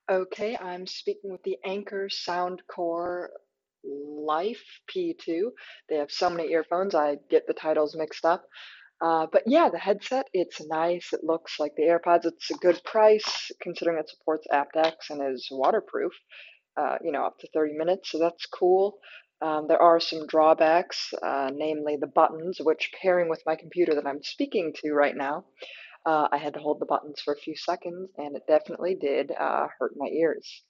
Microphone quality
The Anker SoundCore Life P2 microphone heavily attenuates low vocals, meaning most people will sound “distant” or “muffled” when taking hands-free calls.
Despite the impressive jargon surrounding the four-microphone array, mic quality isn’t very good. Low-frequency notes are de-emphasized to the detriment of clarity.
Anker SoundCore Life P2 microphone demo:
Anker-Soundcore-Life-P2-microphone-demo.mp3